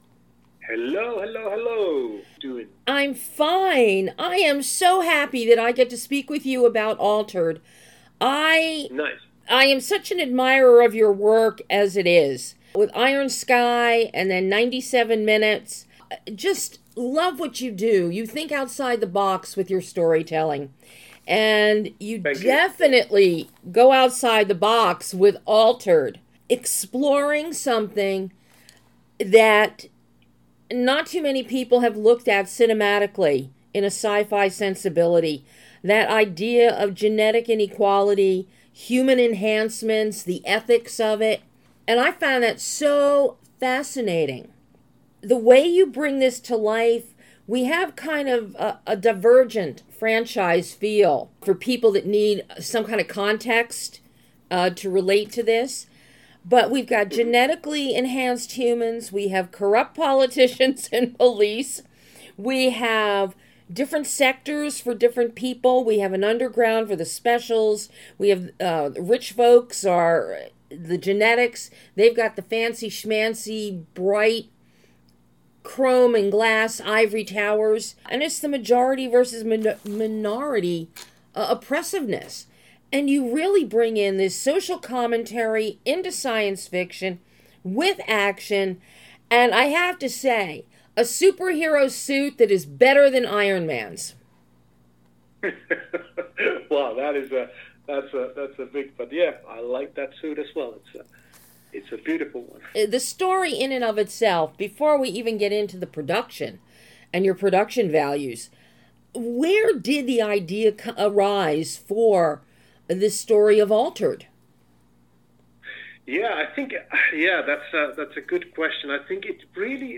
An exclusive in-depth interview with writer/director TIMO VUORENSOLA, breaking down his fascinating and exciting futuristic film, ALTERED.